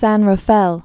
(săn rə-fĕl)